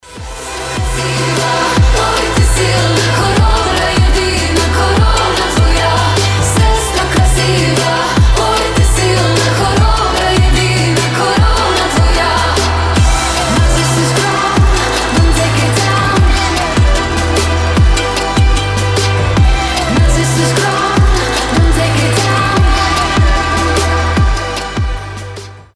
• Качество: 320, Stereo
фолк
вдохновляющие